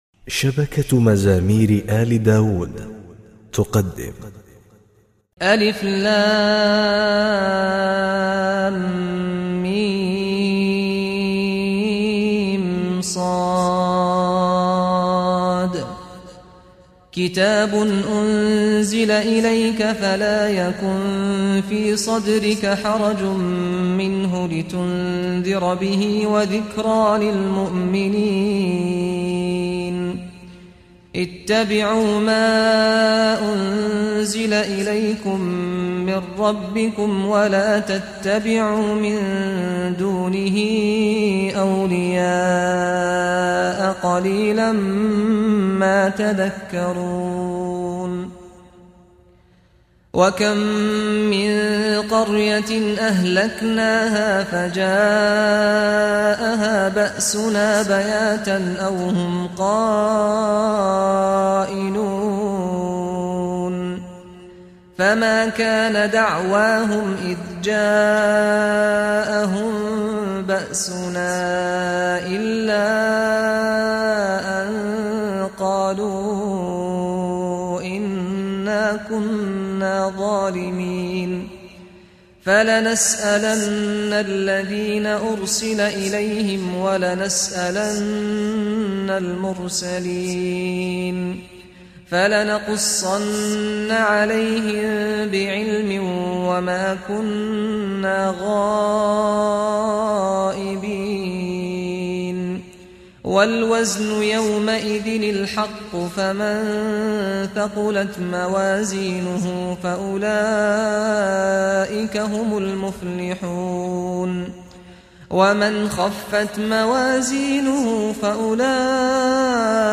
مقام الصبا
• إسم الشيخ : مقاطع لمقام البيات بصوت عدة شيوخ
مقام يمتاز بالروحانية الجياشة والعاطفة،يبعث إلى إلى الحزن والشفافية، اذ يستطيع المقرئ أن يعبر من خلاله باحساس مع الآيات باستخدام قرار النغمات الصوتية وجوابها بطريقة تتابعية منتظمة، لذلك يحبذ للمقرئ أن يقرأ هذه الآيات الروحانية والآيات التي تتحدث عن أهوال يوم القيامة بهذا المقام .
الأول : جنس صبا على درجة الدكاه
الثاني : جنس كرد على درجة الحسيني .
سعد الغامدي 3